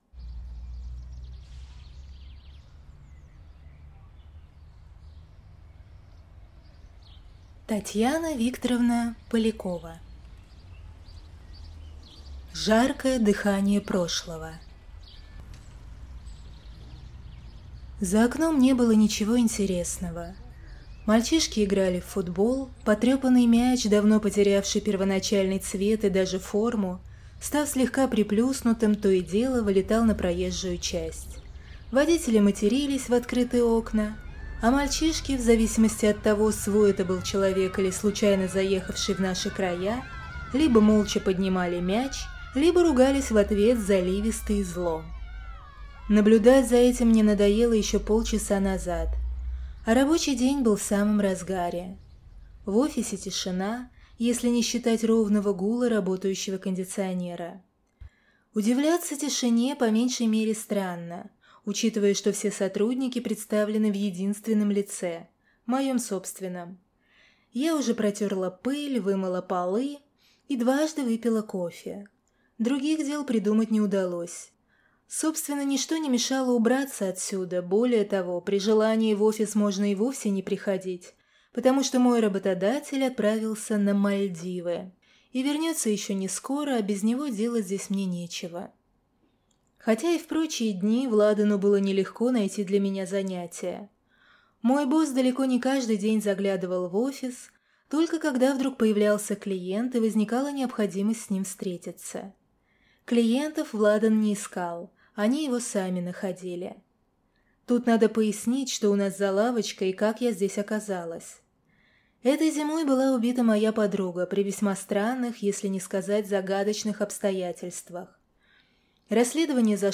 Аудиокнига Жаркое дыхание прошлого - купить, скачать и слушать онлайн | КнигоПоиск